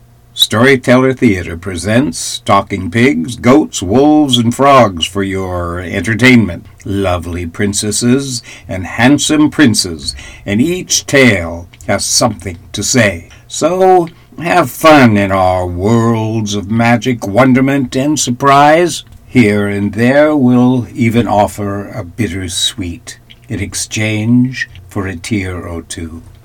Children’s Stories & Fairy Tales Audio Book!